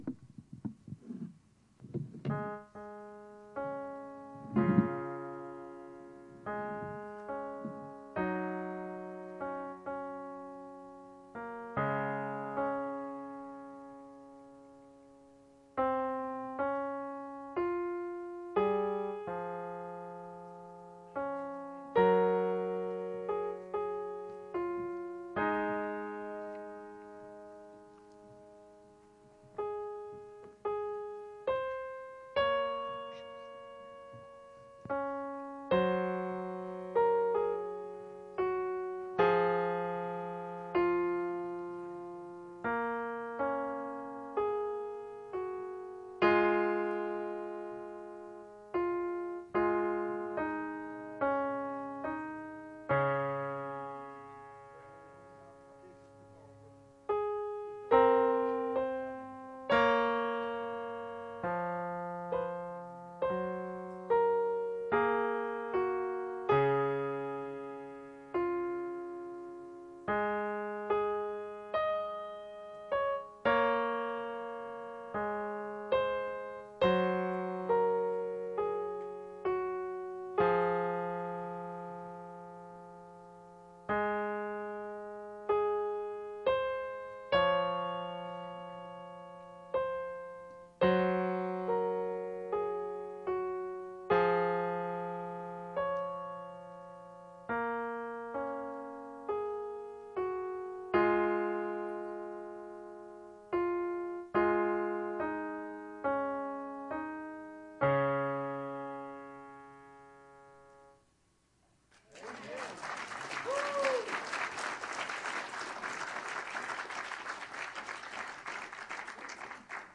Bible Text: Numbers 13:25-33; 14:1-11 | Preacher